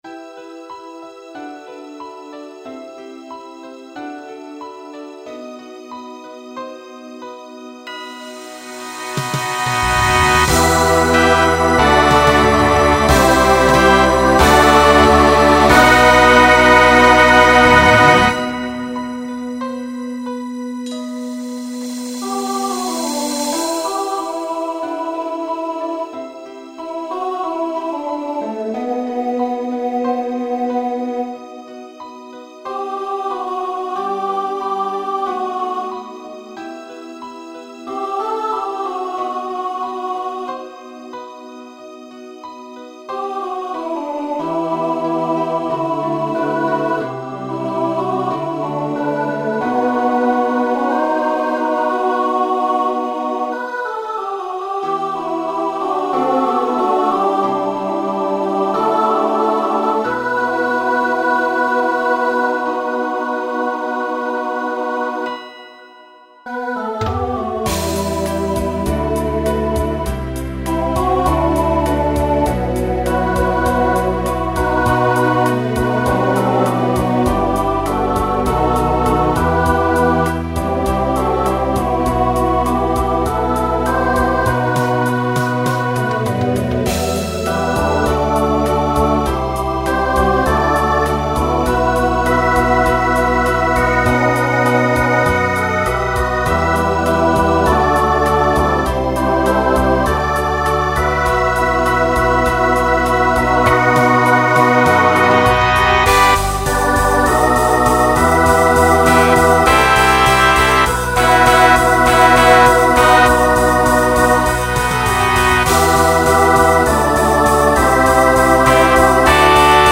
Genre Rock Instrumental combo
Solo Feature Voicing SATB